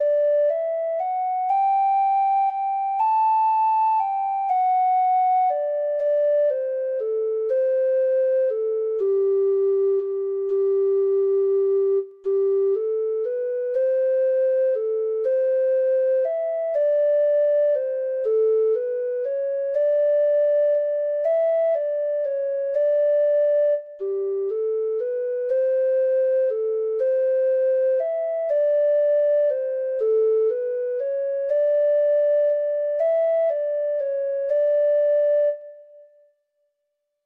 Traditional Trad. Charming Mary O'Neill (Irish Folk Song) (Ireland) Treble Clef Instrument version
Irish